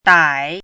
chinese-voice - 汉字语音库
dai3.mp3